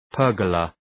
Προφορά
{‘pɜ:rgələ}
pergola.mp3